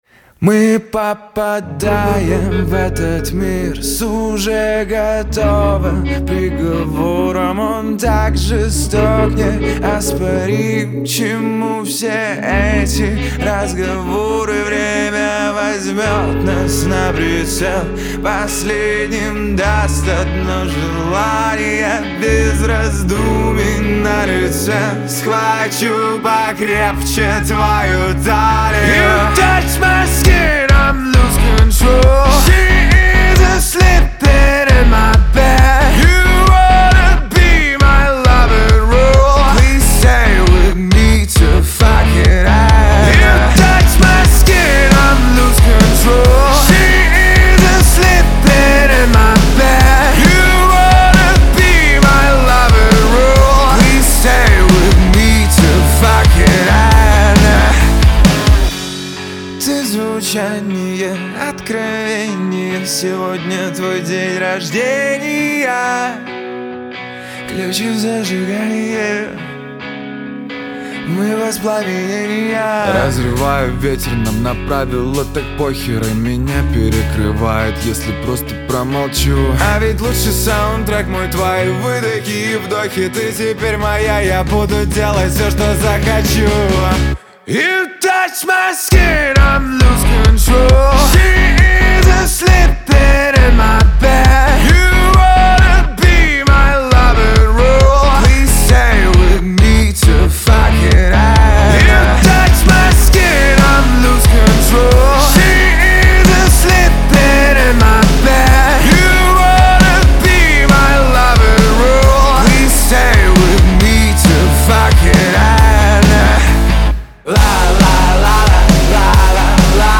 Трек размещён в разделе Русские песни / Рок.